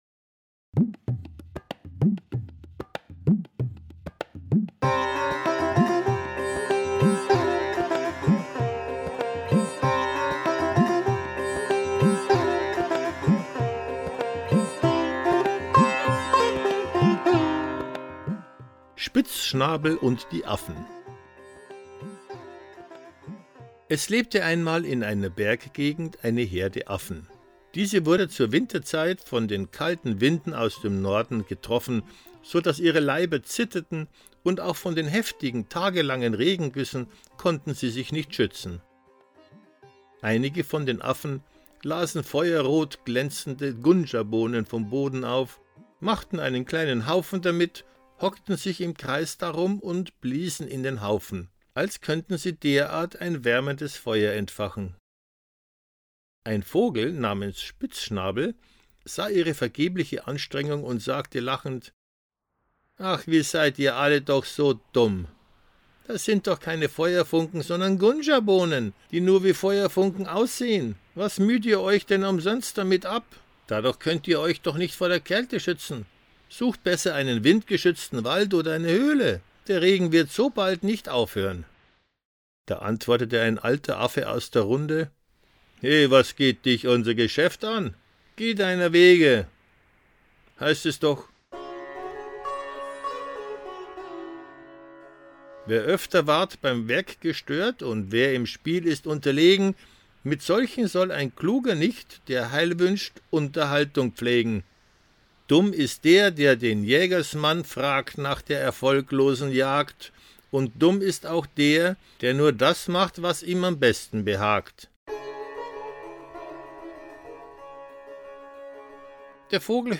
Hörbuch
Lese- und Medienproben